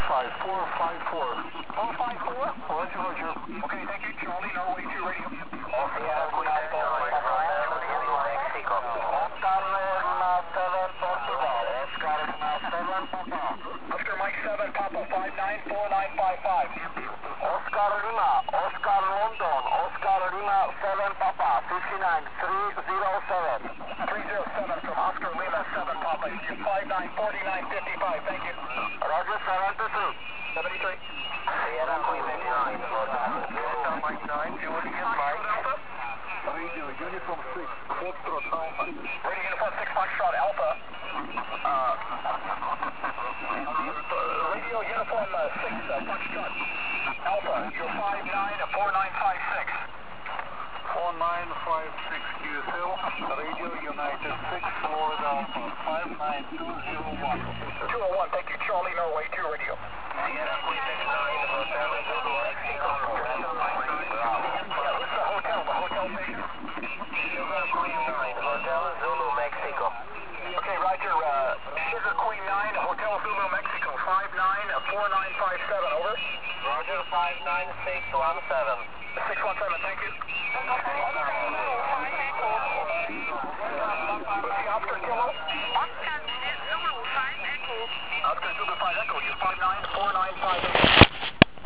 (V dalším jsou záznamy z tohoto závodu)